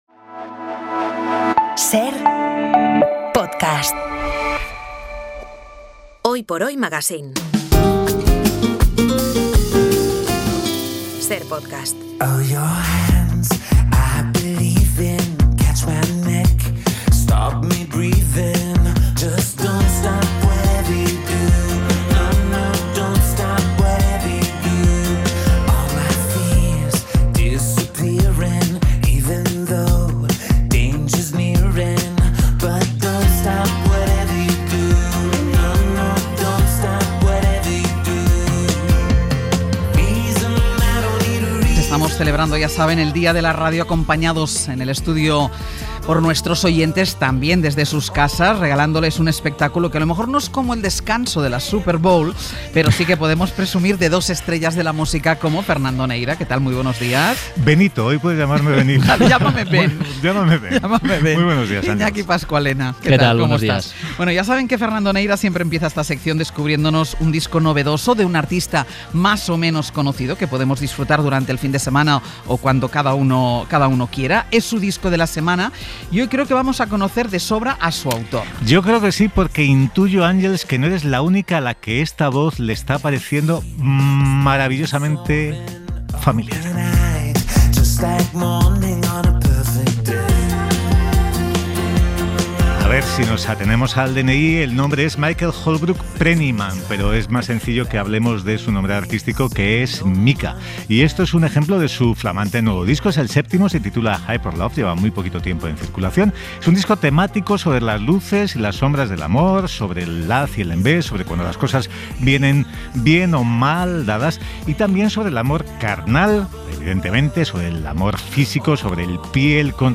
Además, recibimos la visita de los legendarios 091, que vienen presumiendo de su nuevo elepé "Espejismo nº9", el noveno de su larguísima trayectoria.